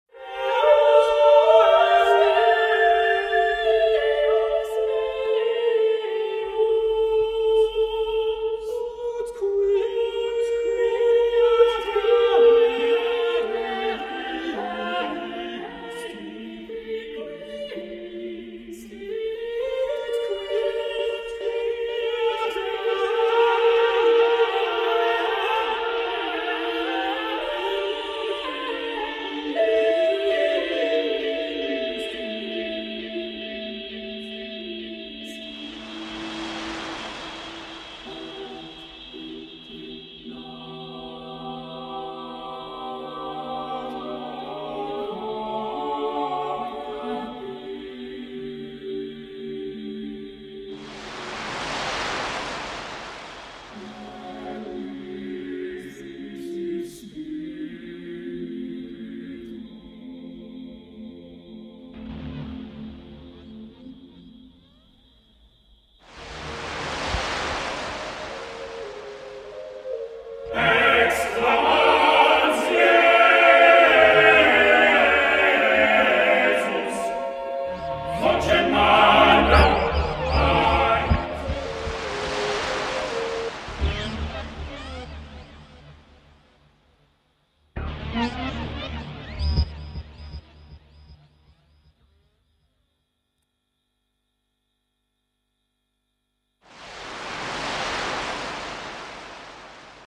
エレピやオペラ風サンプルを配しトビを効かせたミニマル・ハウス